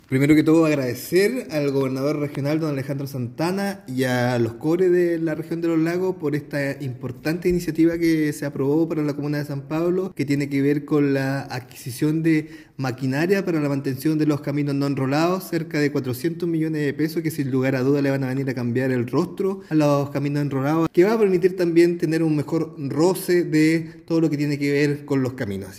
El alcalde de San Pablo, Marco Carrillo, valoró esta aprobación como un paso fundamental para el desarrollo local.
alcalde-carrillo-.mp3